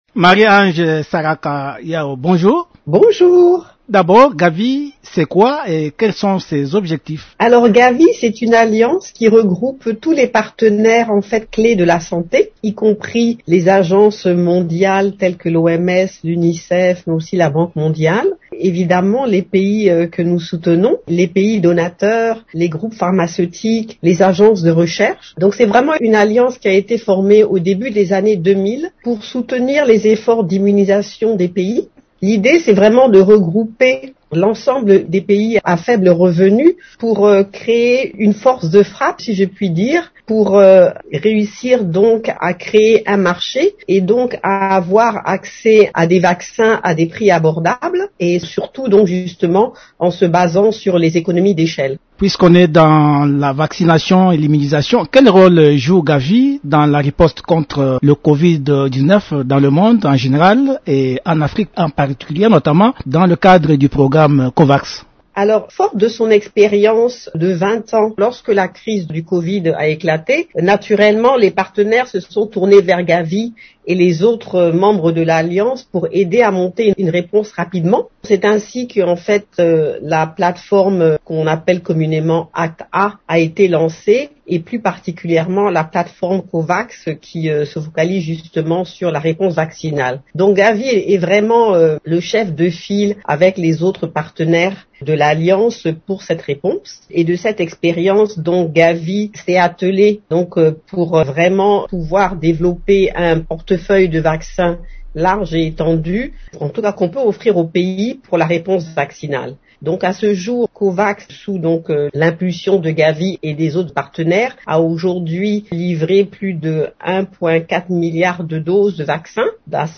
Dans une interview exclusive à Radio Okapi vendredi 8 avril